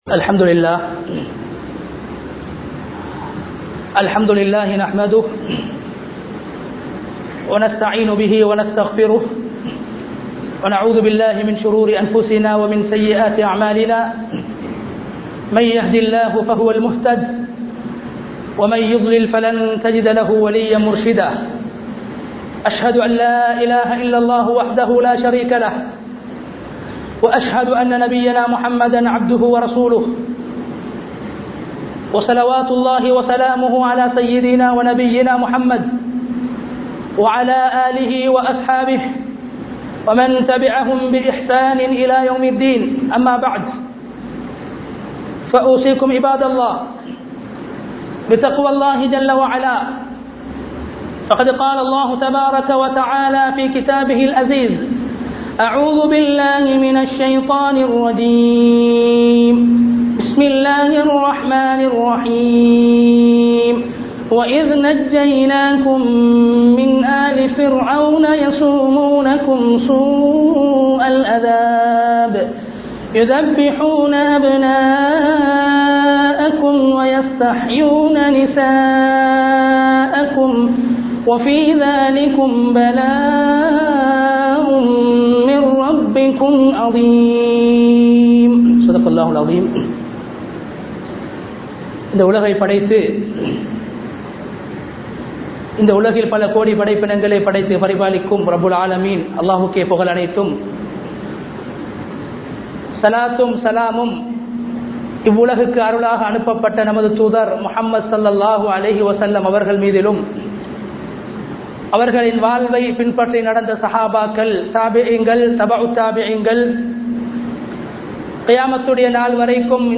ஆசூரா கூறும் 03 படிப்பினைகள் (3 Highlights of the Ashoora) | Audio Bayans | All Ceylon Muslim Youth Community | Addalaichenai
Town Jumua Masjidh